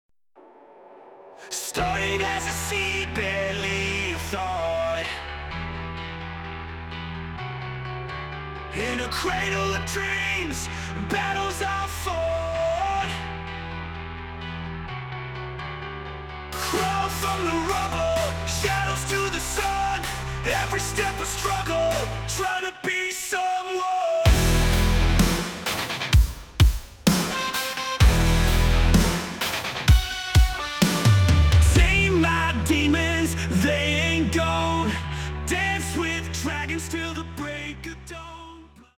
An incredible Rock song, creative and inspiring.